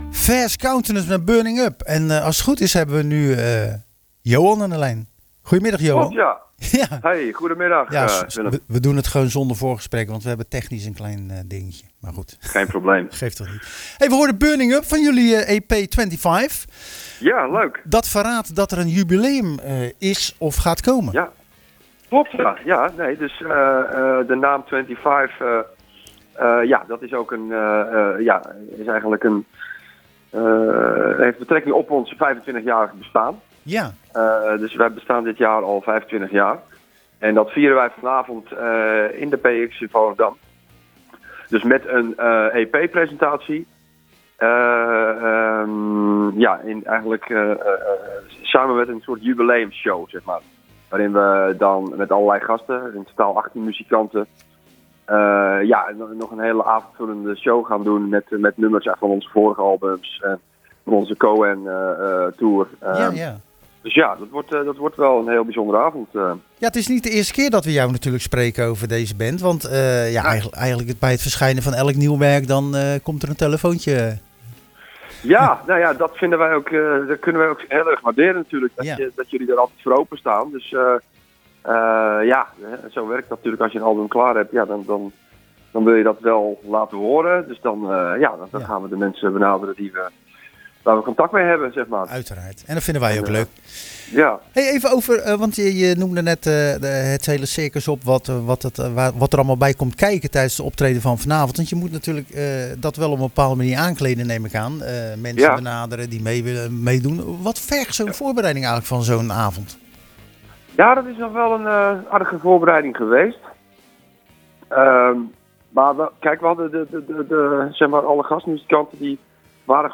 We belde tijdens het programma Zwaardvis